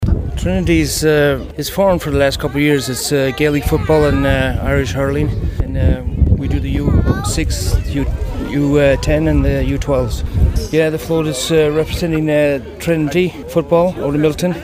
St. Patrick’s Day Parade Fun in Scituate